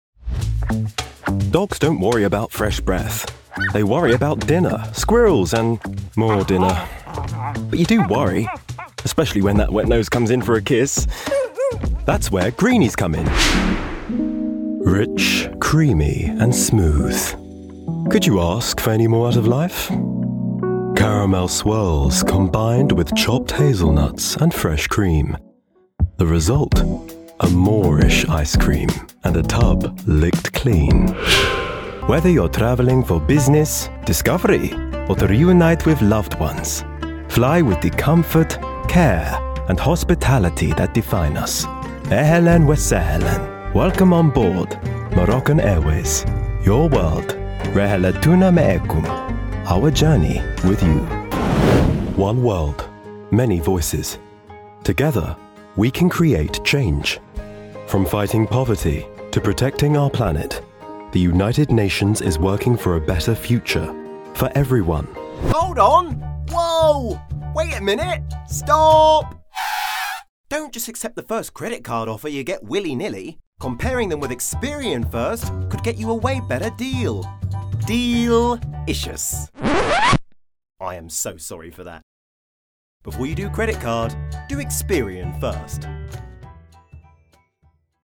3. Commercial
HOME STUDIO
RP
African, American, Estuary, London, RP, Russian